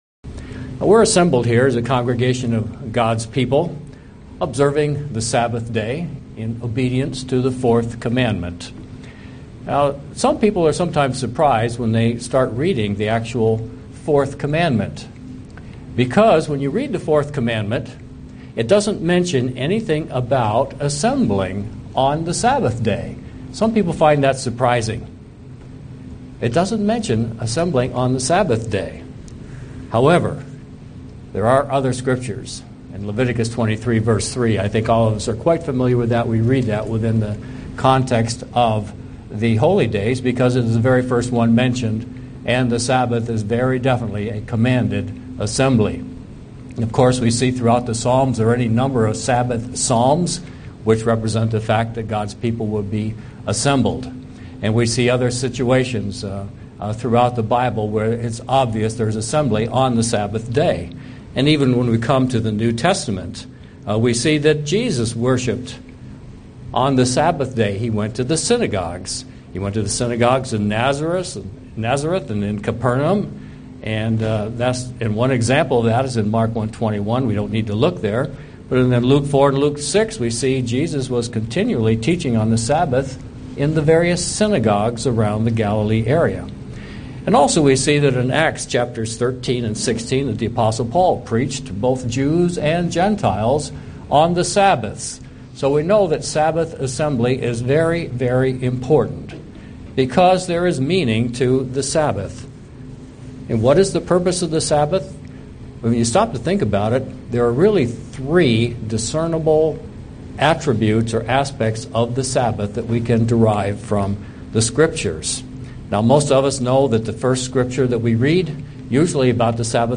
Given in Buffalo, NY
UCG Sermon Studying the bible?